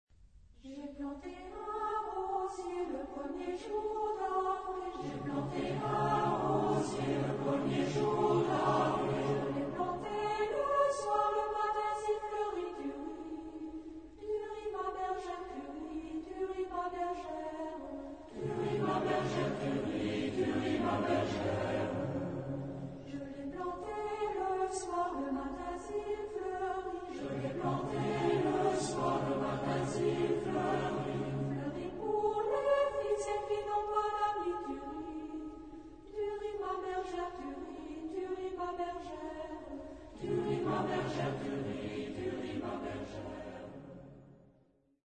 Genre-Style-Forme : Populaire ; Traditionnel ; Chanson ; Danse ; Profane ; Chanson à répétition
Caractère de la pièce : joyeux
Type de choeur : SATB  (4 voix mixtes )
Solistes : 1 au choix  (1 soliste(s))
Tonalité : ré majeur